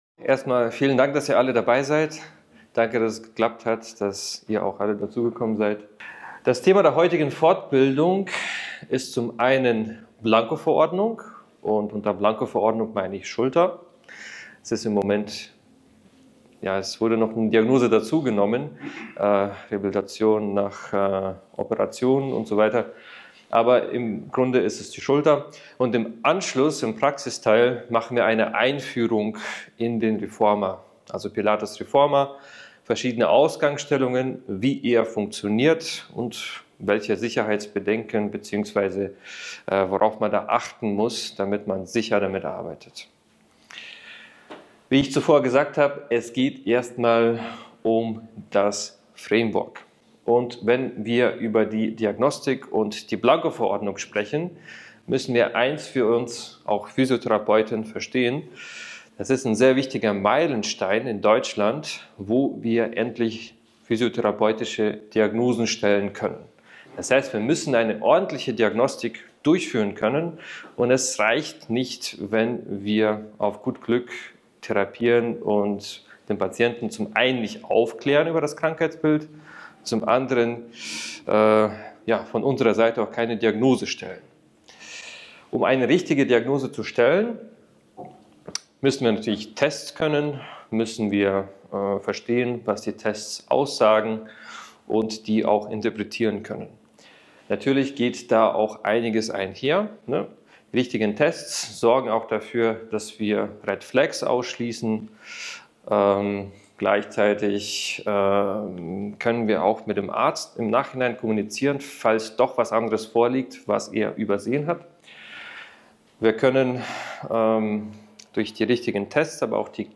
In dieser Fortbildung wird die Blankoverordnung im Kontext der Schulterdiagnostik behandelt. Der Referent erklärt die Bedeutung einer ordentlichen Diagnostik und die Notwendigkeit, Tests durchzuführen, um die richtige Behandlung zu gewährleisten. Es werden verschiedene Subgruppen von Schulterdiagnosen vorgestellt, die häufig in der Physiotherapie vorkommen, sowie deren spezifische Tests und Behandlungsmöglichkeiten.